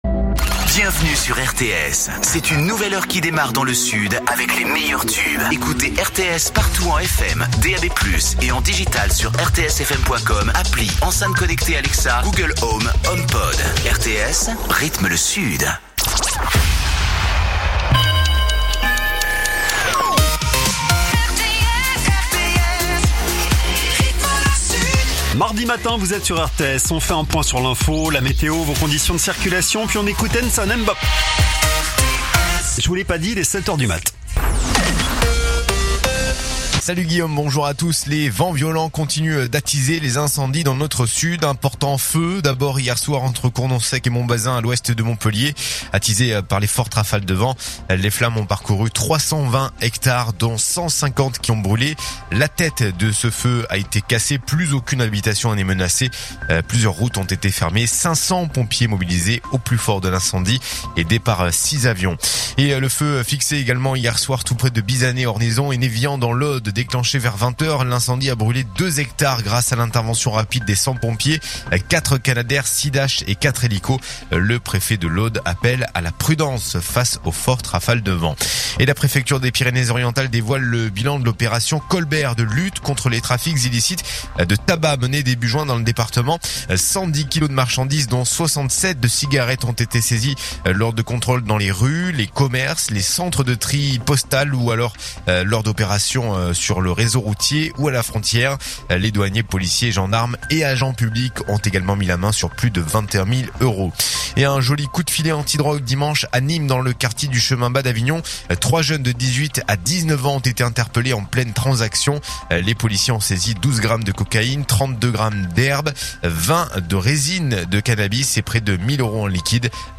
Écoutez les dernières actus d'Avignon en 3 min : faits divers, économie, politique, sport, météo. 7h,7h30,8h,8h30,9h,17h,18h,19h.